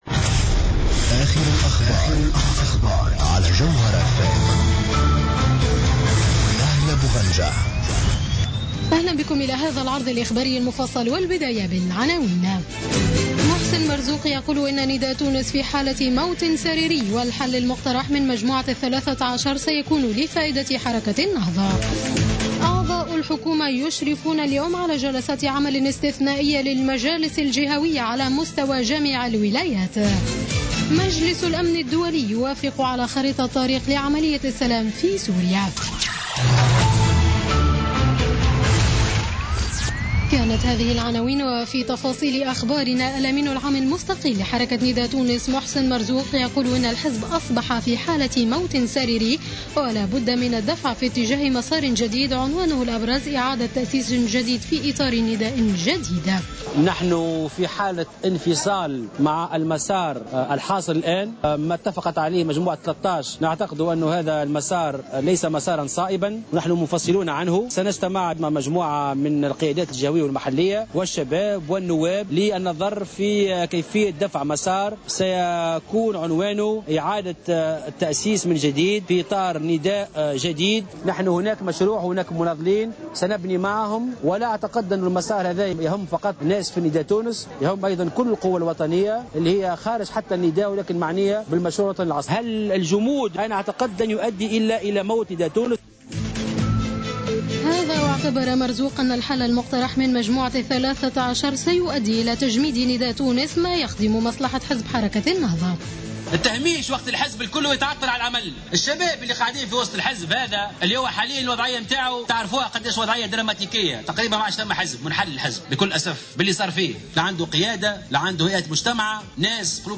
نشرة أخبار منتصف الليل ليوم السبت 19 ديسمبر 2015